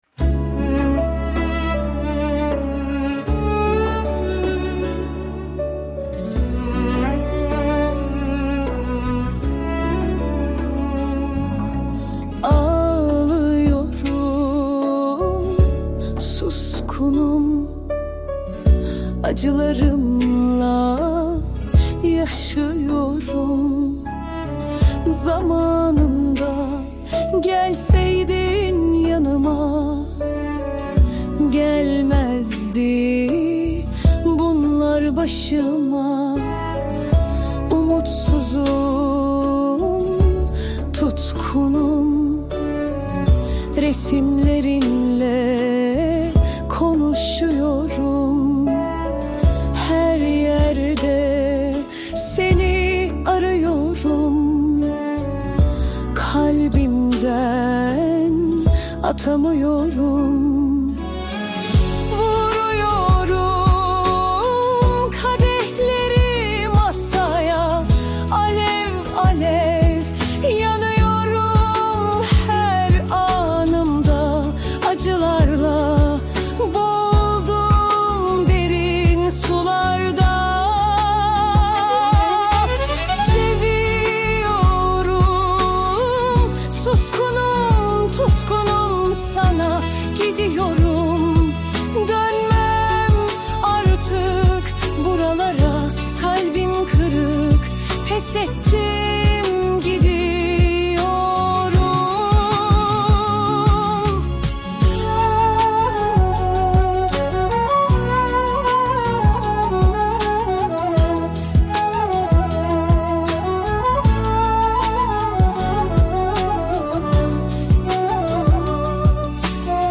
Alaturka pop tarzında, duygusal bir anlatımla yazılmış.
Tür : Alaturka Pop